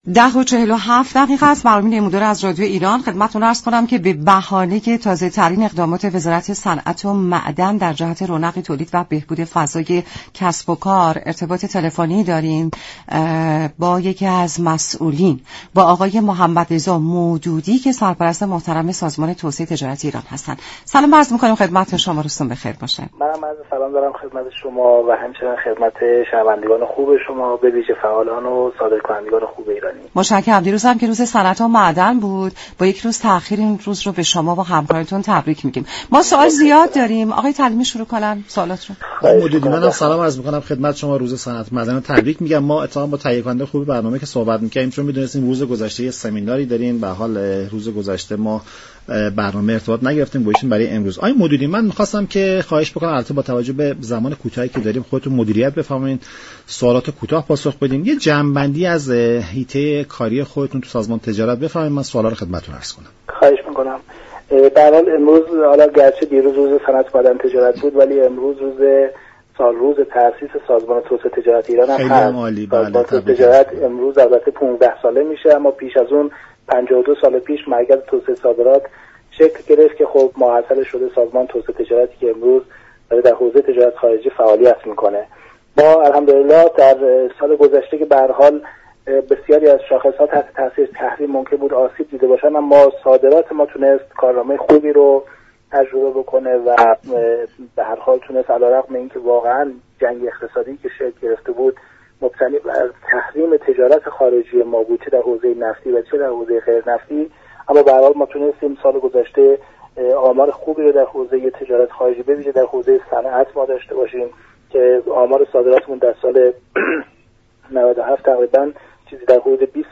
سرپرست سازمان توسعه تجارت ایران در گفت و گو با رادیو ایران گفت: ورود افرد غیر متخصص، صادرات كشور را با آسیب های جدی مواجه كرده است.